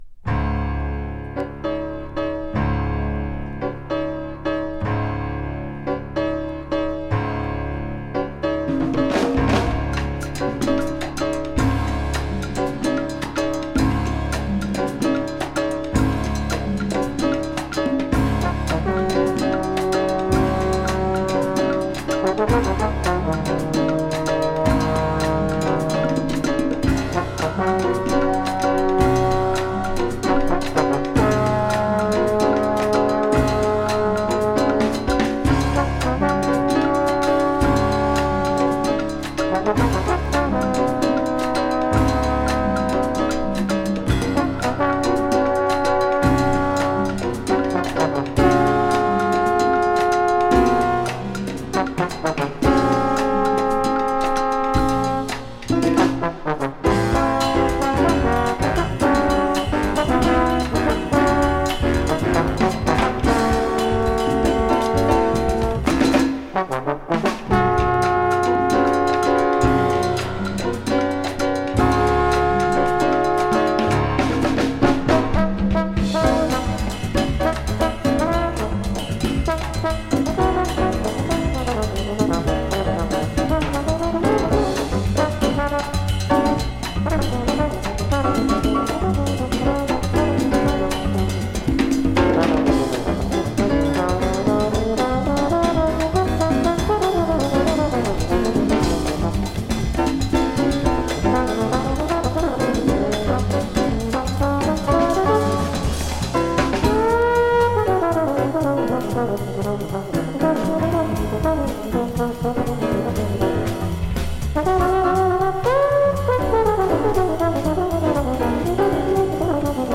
【CONTEMPORARY】【JAZZ FUNK】
コンテンポラリー・ジャズファンク！